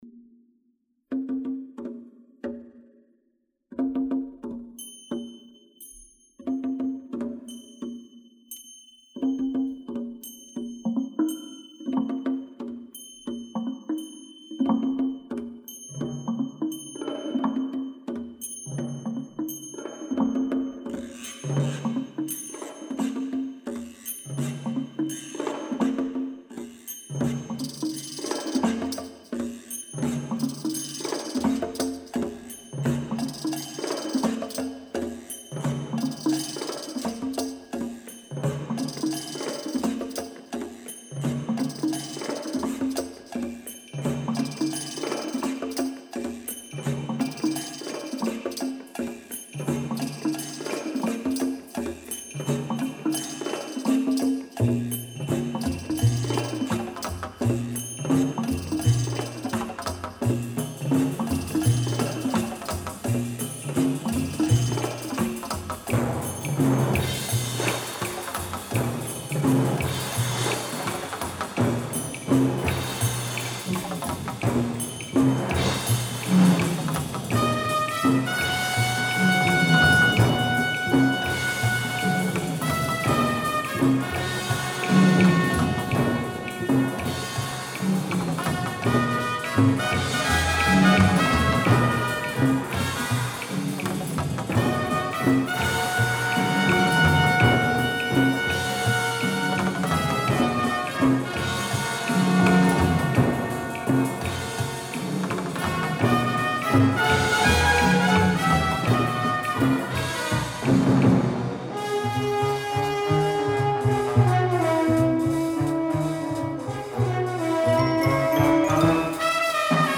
ping-pong stereo, exotica, bachelor pad music